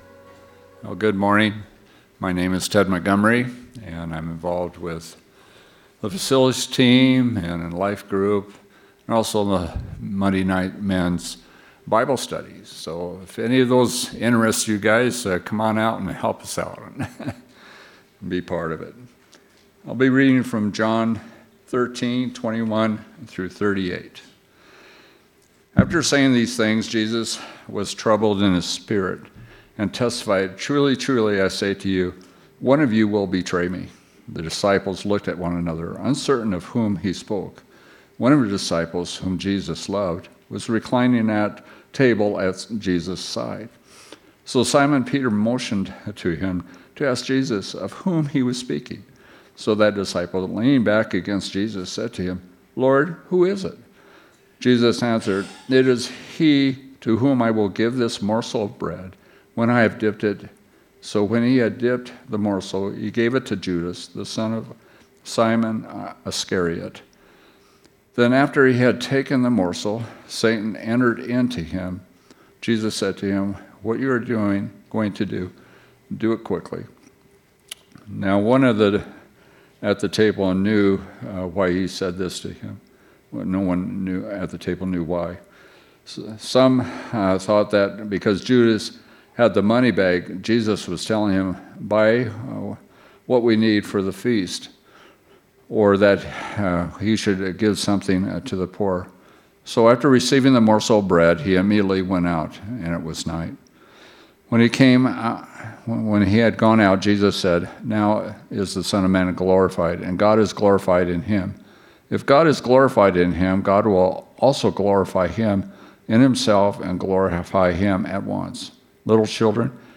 A Calvary podcast where we take what happened over the weekend and connect it with your real life lived Monday through Friday.